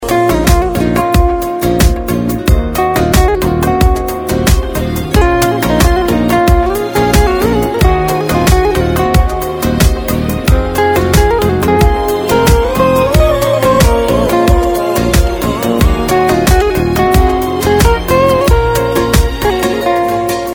آهنگ موبایل عاشقانه